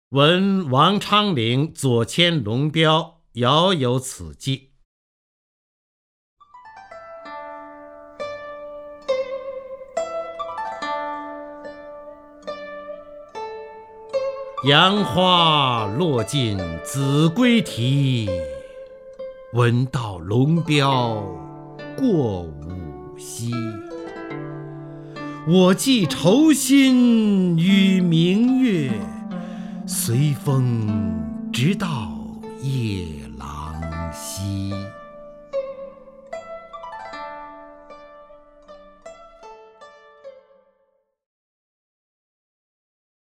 首页 视听 名家朗诵欣赏 方明
方明朗诵：《闻王昌龄左迁龙标遥有此寄》(（唐）李白)　/ （唐）李白